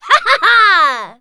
jess_kill_01.wav